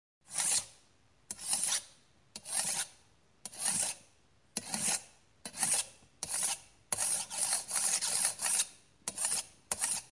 金属掉落在混凝土表面
用我的iphone 4s录制。
Tag: 打击 混凝土 金属表面 螺丝刀 金属 下落 工具 命中 冲击